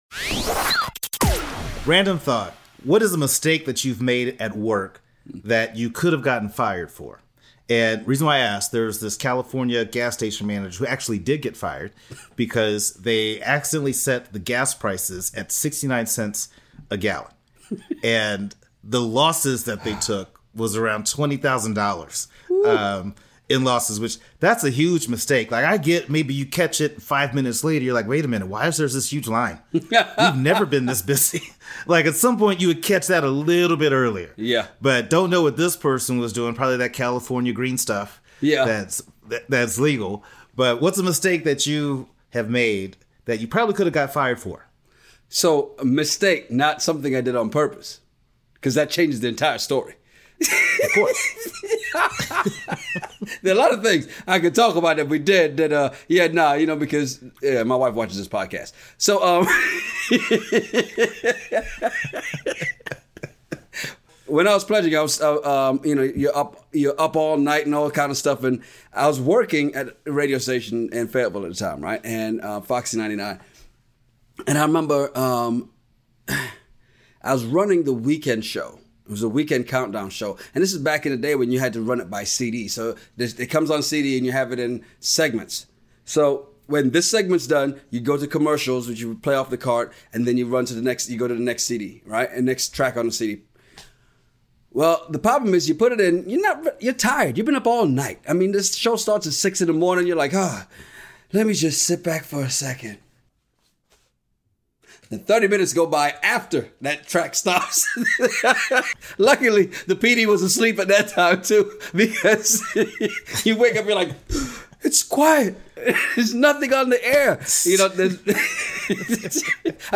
Ever been somewhere and overheard two guys having a crazy conversation over random topics?